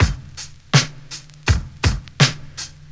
• 82 Bpm 2000s Drum Groove F# Key.wav
Free drum groove - kick tuned to the F# note. Loudest frequency: 2016Hz